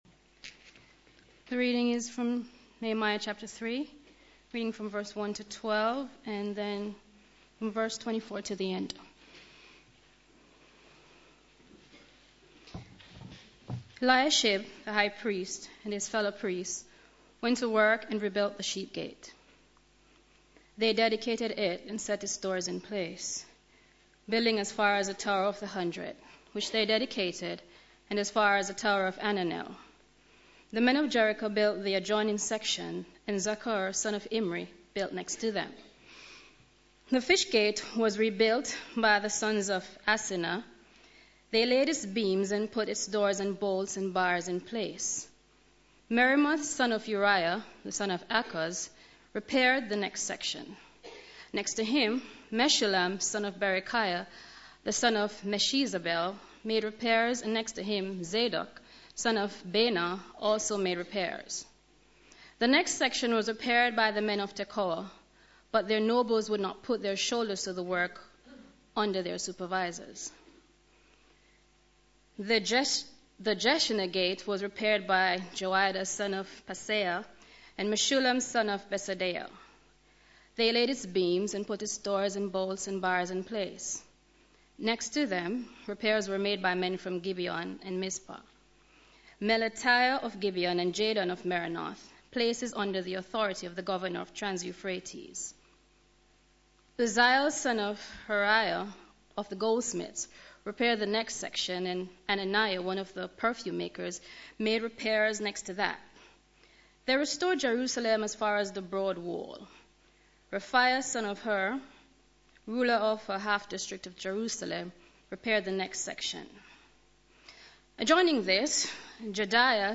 Media for Sunday Service on Sun 17th Jun 2012 11:00
Theme: Many hands make light work Sermon